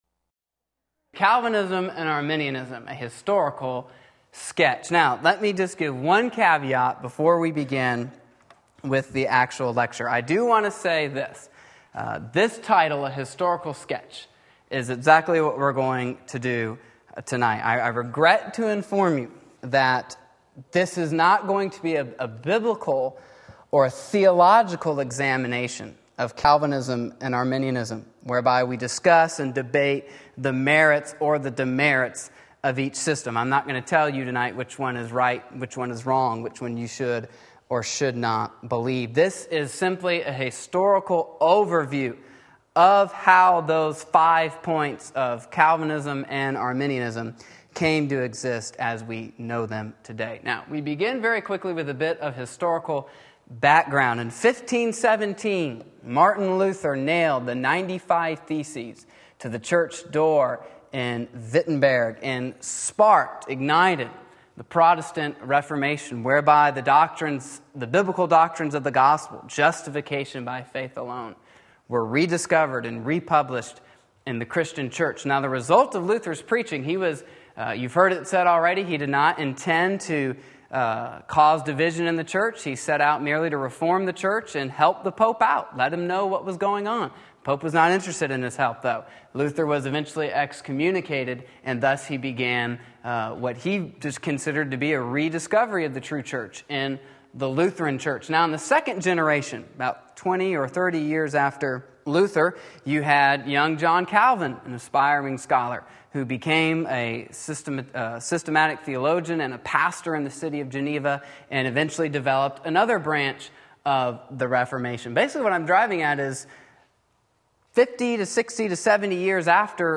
Sermon Link
Wednesday Evening Service